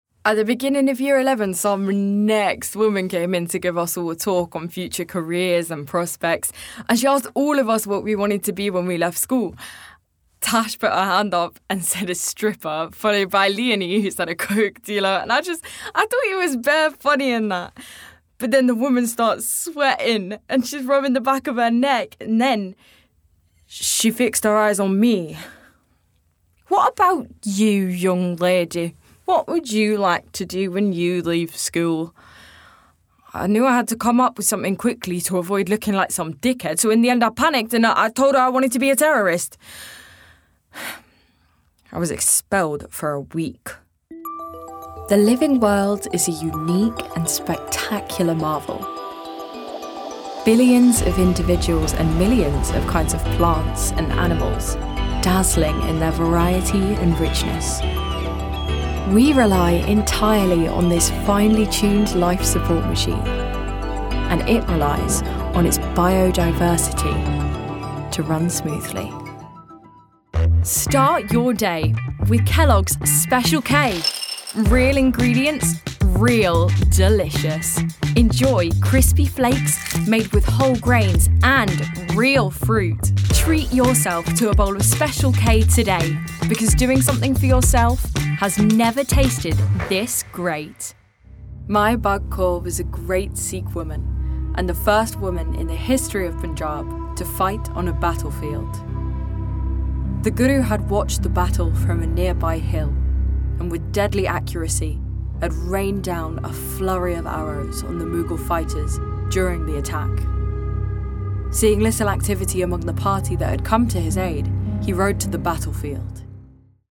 Native voice:
London
Voicereel: